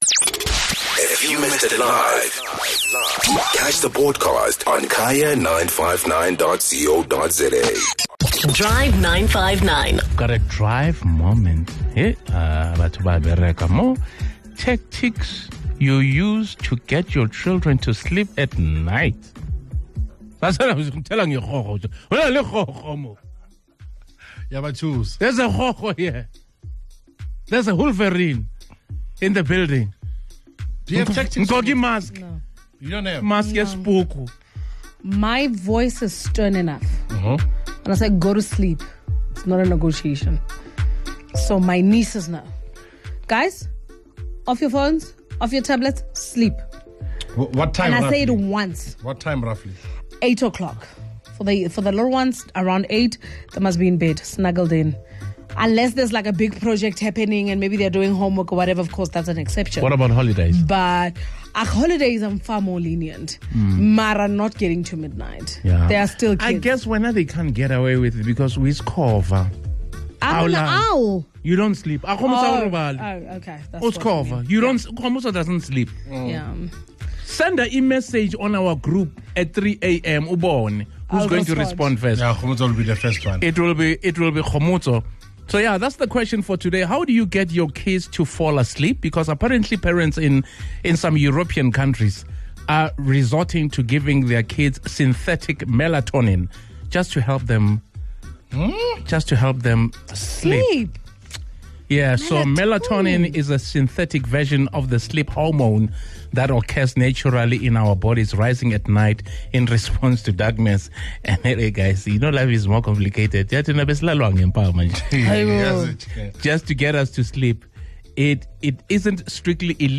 Take a listen to what some of our listeners and the Drive Team had to say!